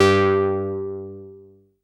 OSC MIXER 1.wav